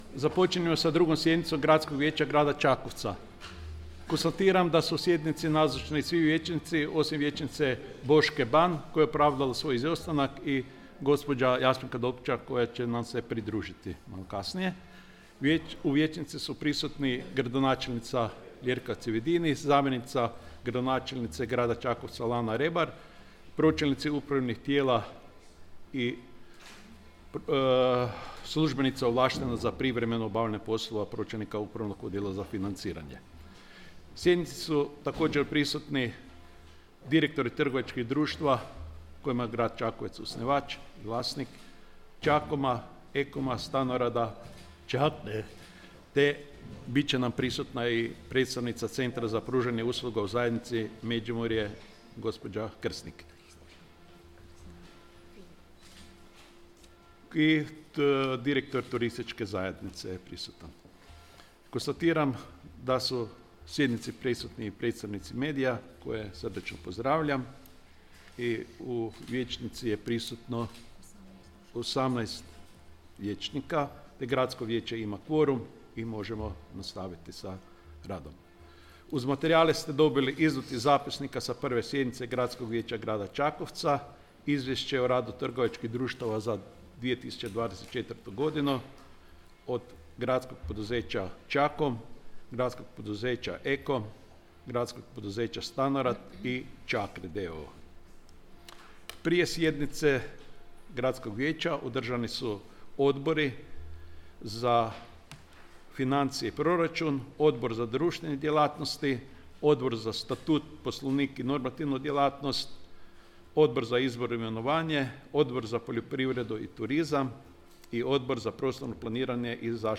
Održana 2. sjednica Gradskog vijeća Grada Čakovca